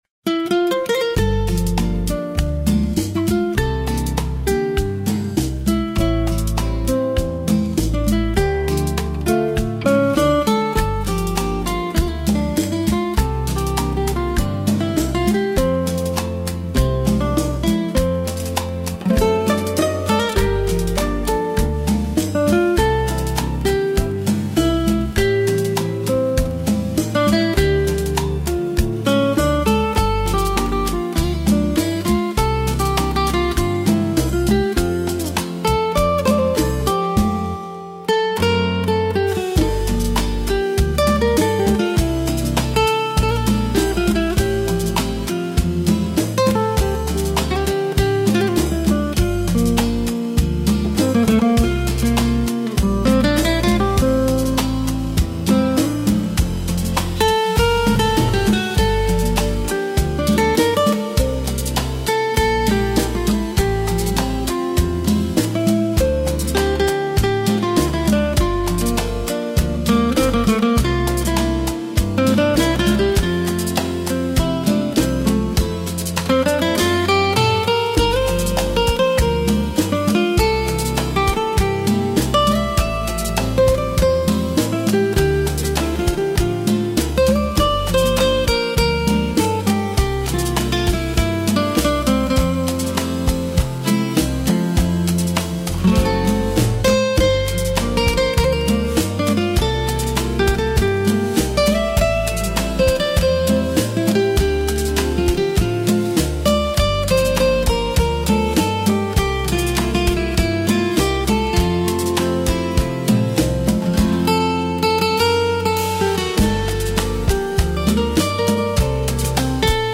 MÚSICA E ARRANJO E VOZ: IA